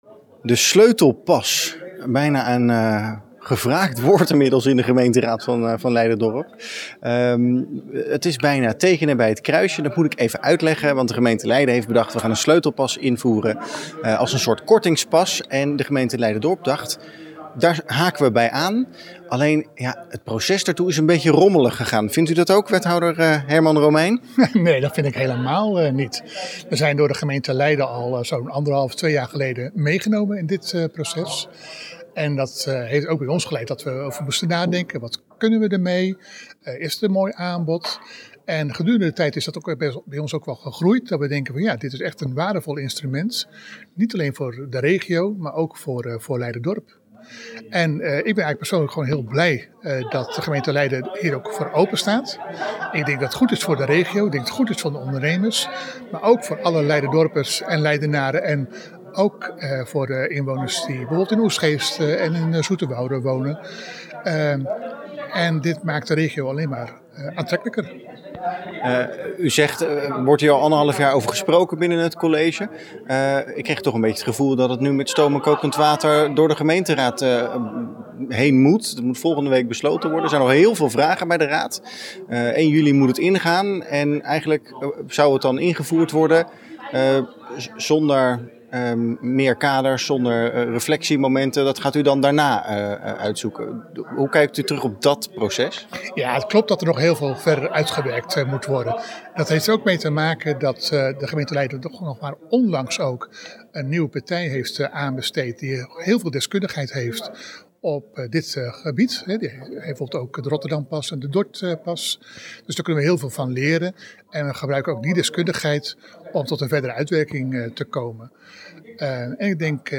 Wethouder Herman Romeijn na het debat over de Sleutelpas.
Wethouder-Herman-Romeijn-over-de-Sleutelpas.mp3